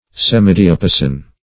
\Sem`i*di`a*pa"son\